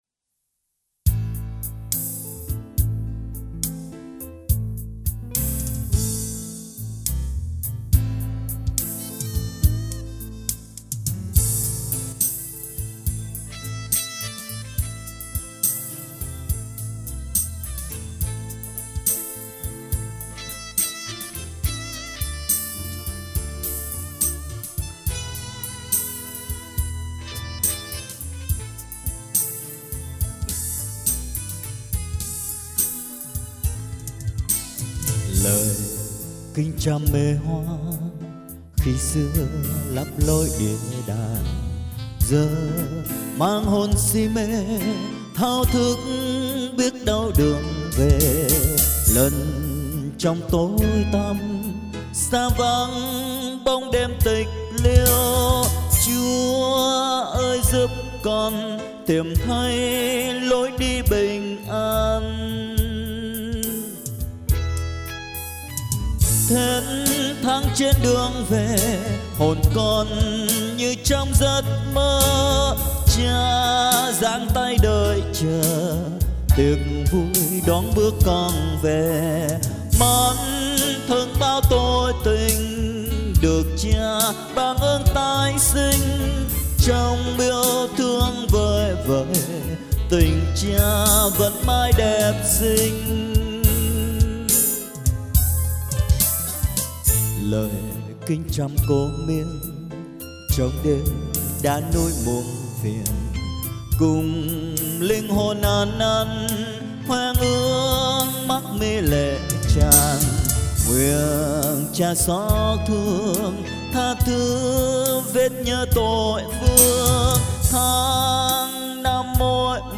Ai mún nghe tiếng "đực rựa" thì dzô nghe thử nè!
tiếng hát của một vị mục sư